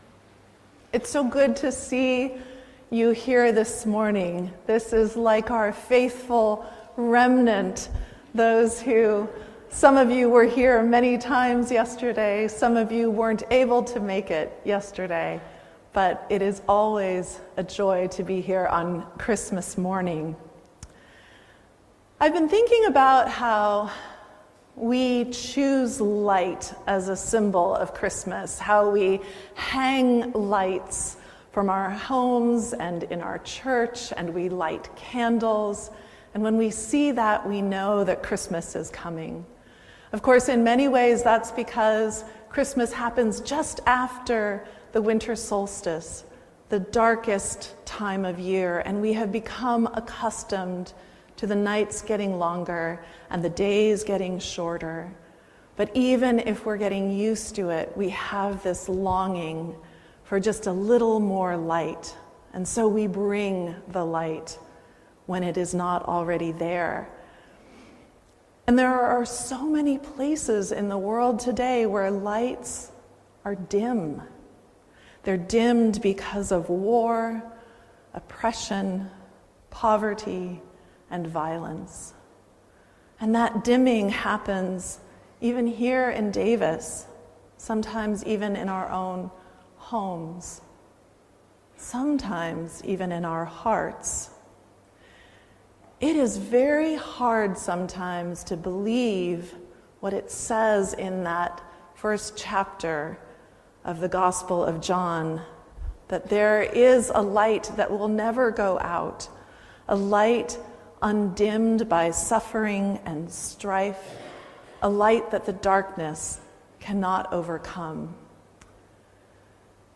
Sermon by:
Sermon for Christmas Day December 25, 2024